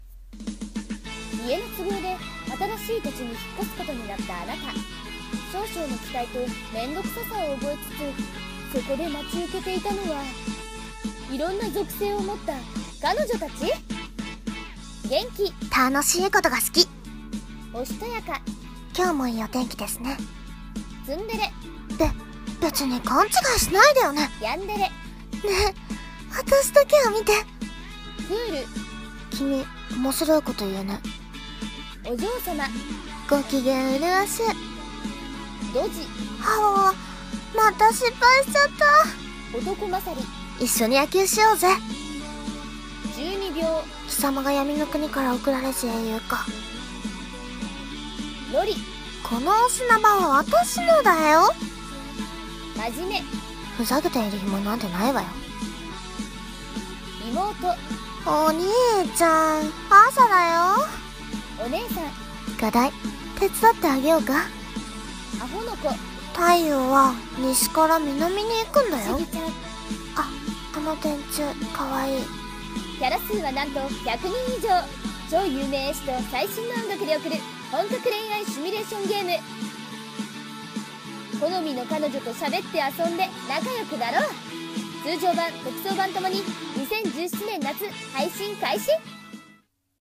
【ゲームCM風声劇】属性彼女っ♪